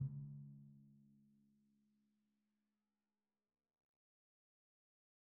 Timpani3_Hit_v1_rr1_Sum.wav